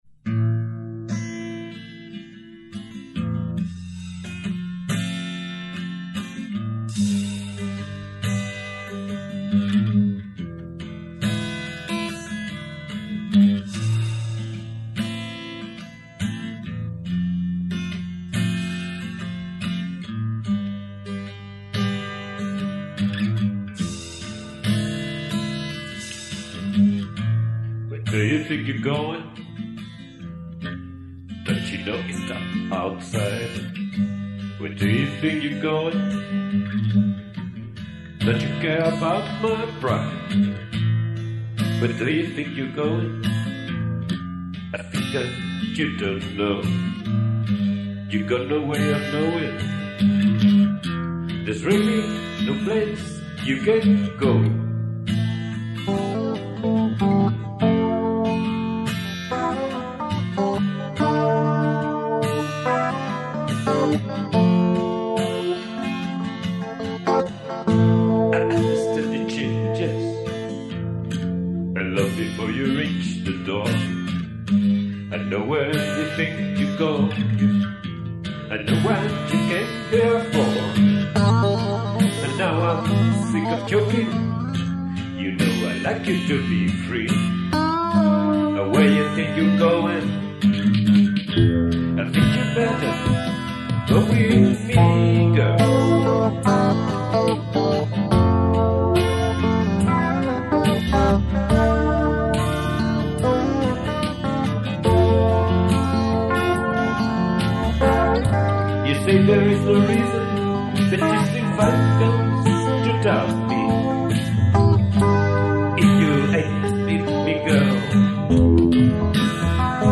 Voice, acoustic and electric guitars and programming.